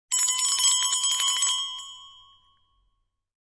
Descarga de Sonidos mp3 Gratis: campana 15.